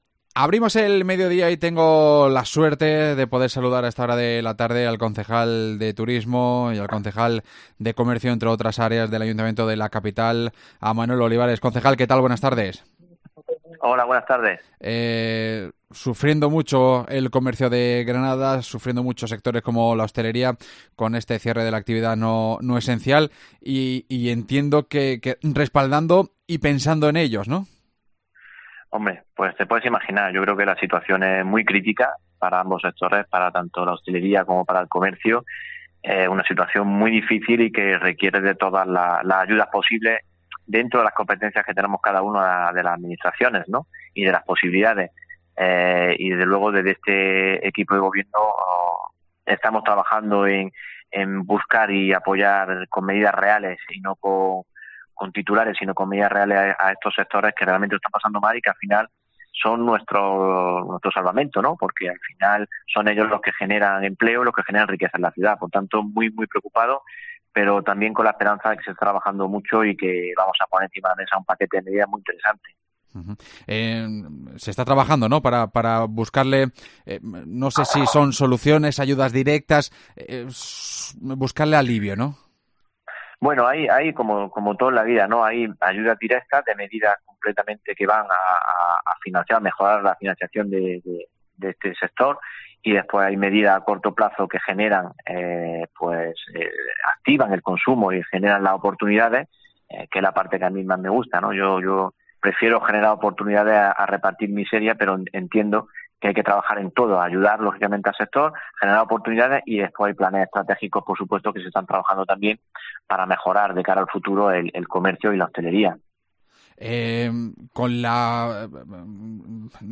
AUDIO: El concejal de turismo y comercio ha estado en COPE Granada repasando la actualidad de la ciudad